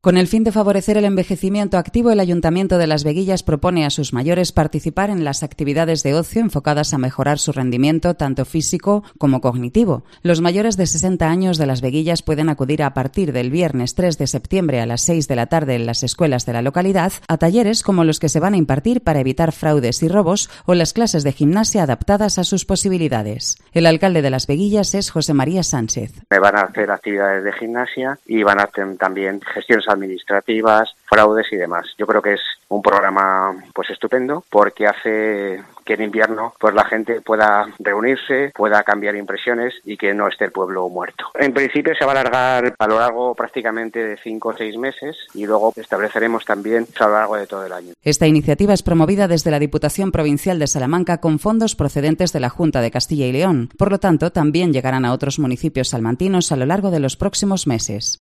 El alcalde de las Veguillas José María Sánchez, anima a los vecinos a participar en el programa Depende de ti